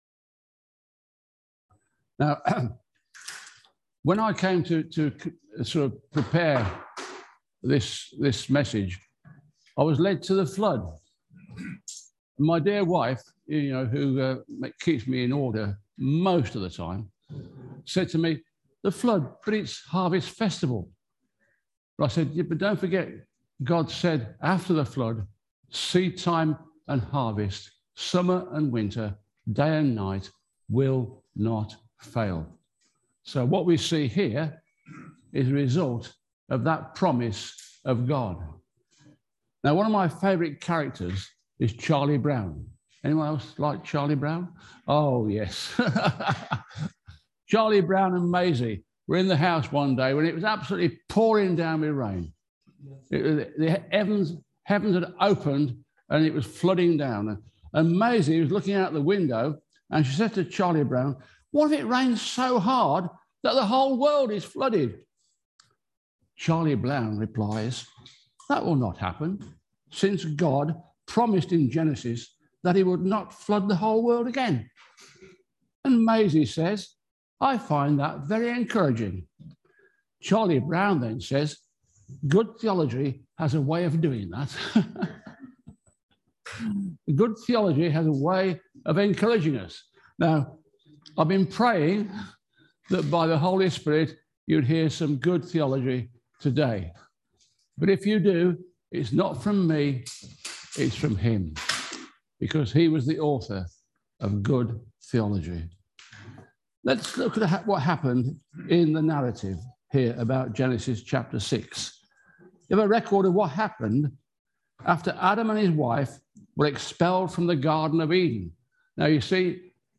Looking to Jesus Passage: Genesis 6:1-8 Service Type: Sunday Service Topics